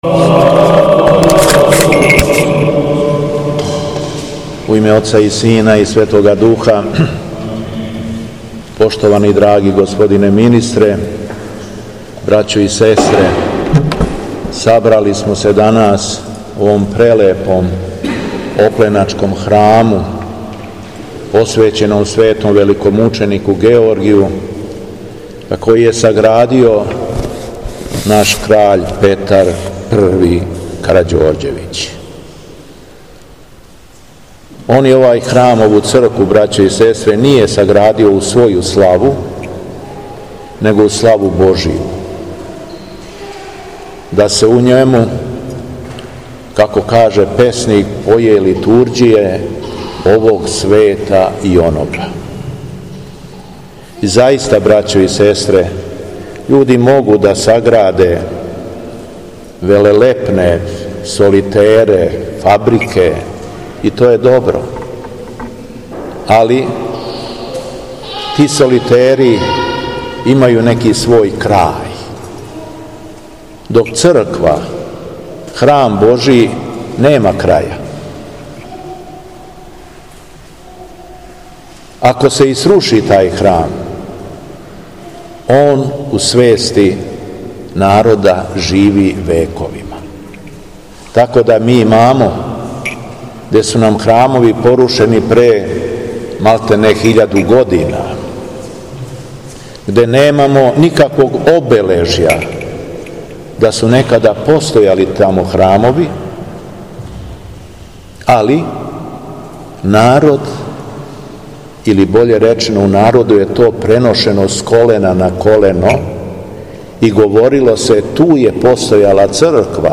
У среду, 16. августа 2023. године, а поводом 102. годишњице од упокојења Краља Петра I Карађорђевића Ослободитеља, Његово Преосвештенство Епископ шумадијски Господин Јован служио је Свету Архијерејску Литургију и парастос Краљу Петру у његовој задужбини Цркви Светог Великомученика Георгија на Опленц...
Беседа Његовог Преосвештенства Епископа шумадијског г. Јована